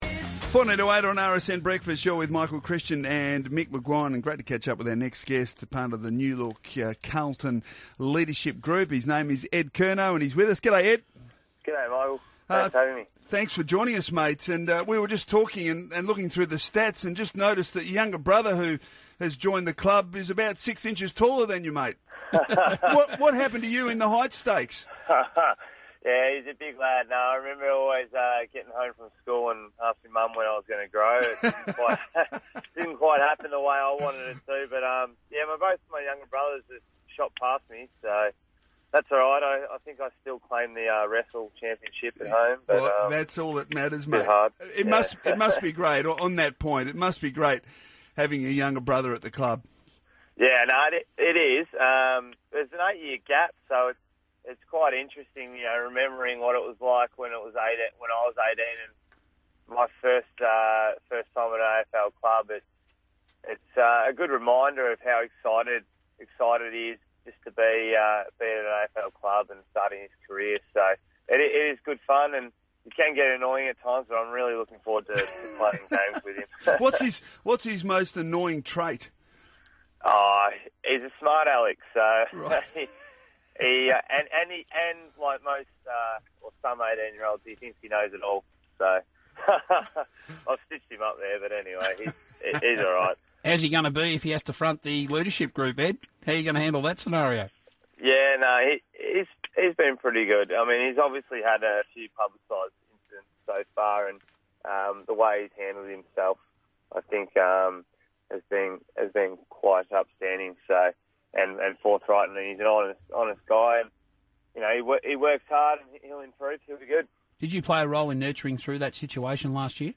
Carlton midfielder Ed Curnow speaks to Radio Sport National ahead of the Blues' NAB Challenge opener against Hawthorn.